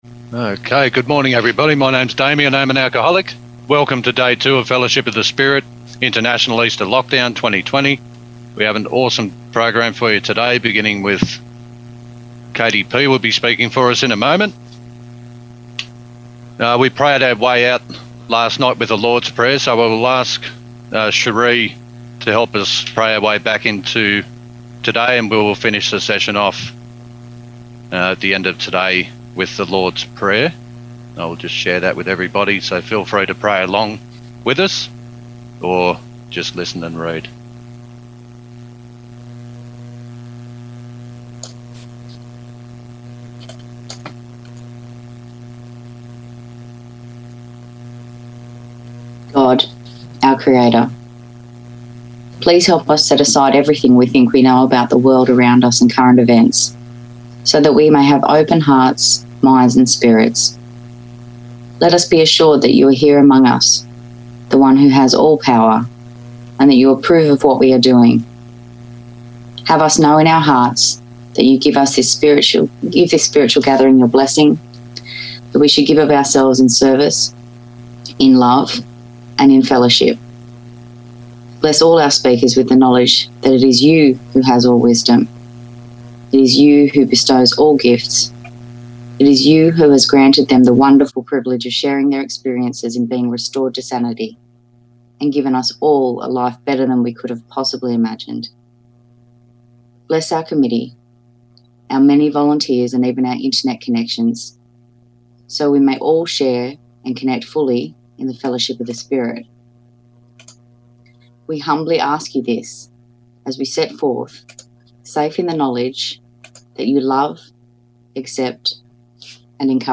Fellowship Of The Spirit International Easter Lockdown Australia &#8211